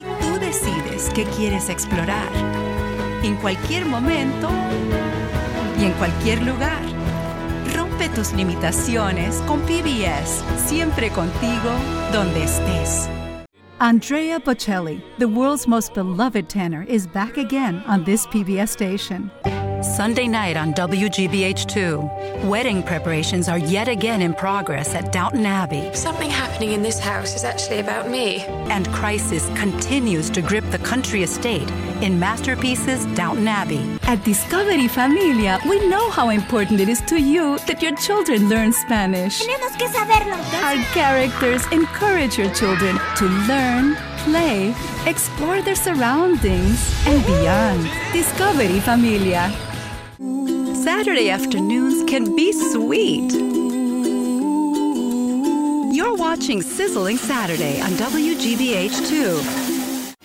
Meine Kunden beschreiben meine Stimme am besten als freundlich, spritzig, ausdrucksstark, angenehm, warm und enthusiastisch